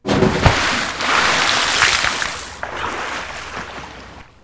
Splash-SFX.wav